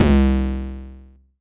808 Kick 2_DN.wav